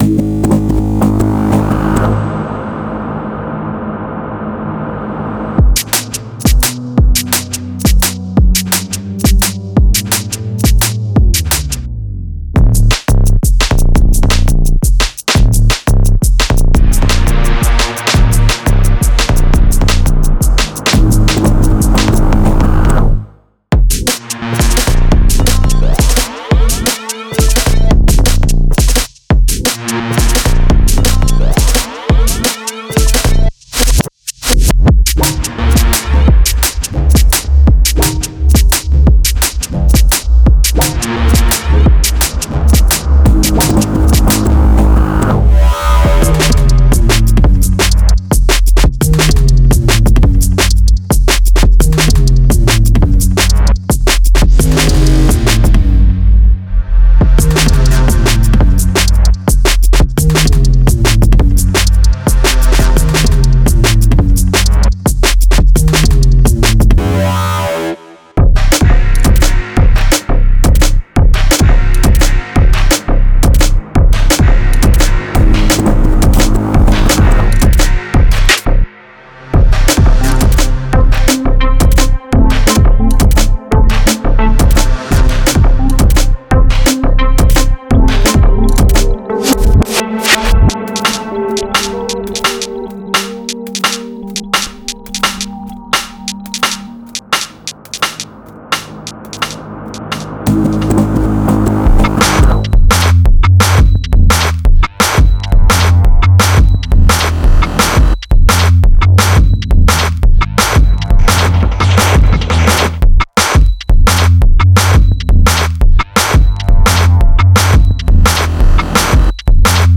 Genre:Drum and Bass
本作は、ドラムンベースのミニマルな側面に完全に特化した内容となっています。
デモサウンドはコチラ↓